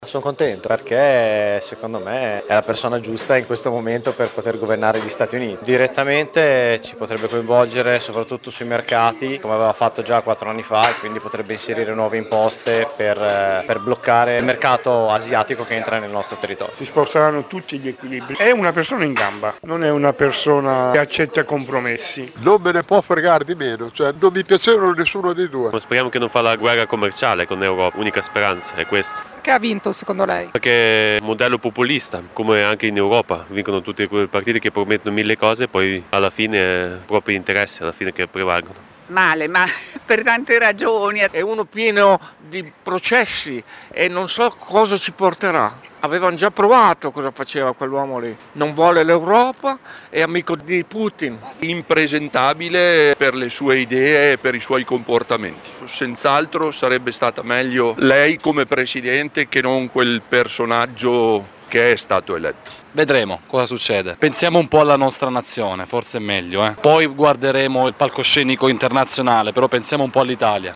Vox-trump.mp3